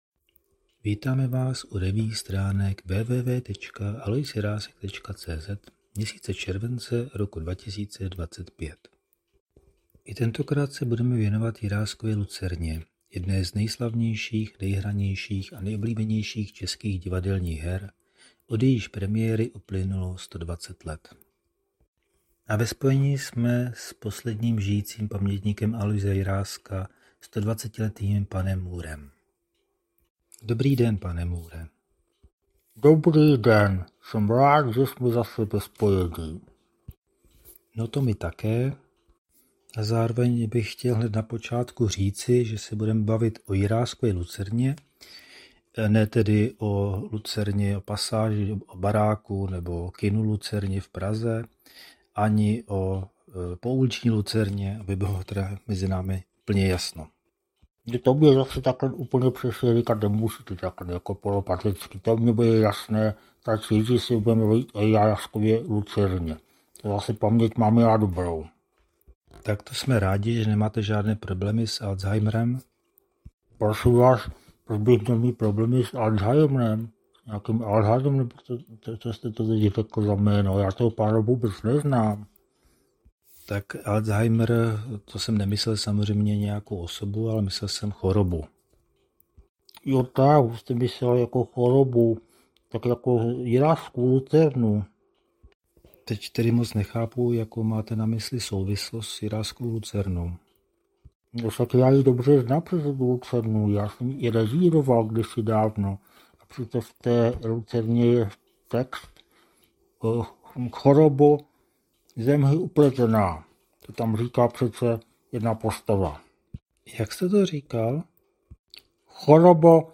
Další amatérský pokus o zvukovou revue přináší s obvyklou humornou nadsázkou druhý díl ze série věnované letošnímu 120. výročí premiéry jedné z nejoblíbenějších a nejhranějších českých divadelních her, Jiráskovy Lucerny, která se odehrála 17.listopadu 1905 v pražském Národním divadle a znamenala okamžitý a trvalý úspěch.